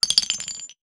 weapon_ammo_drop_18.wav